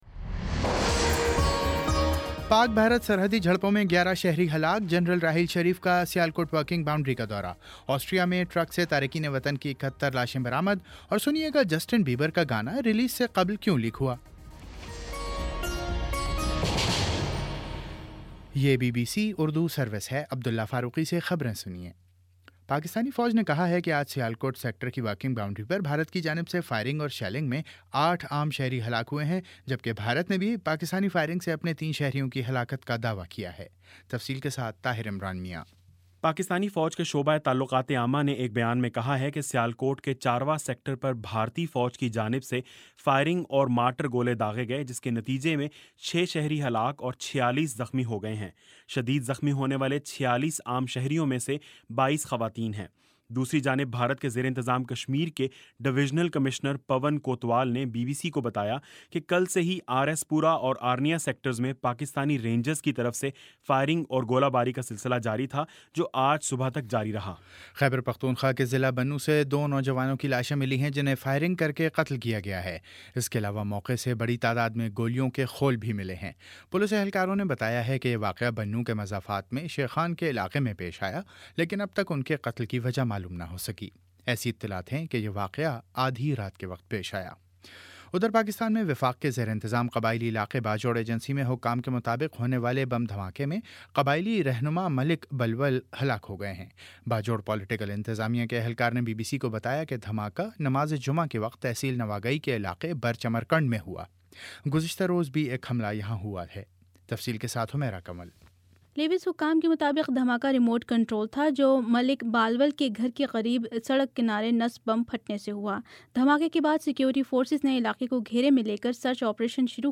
اگست 28: شام چھ بجے کا نیوز بُلیٹن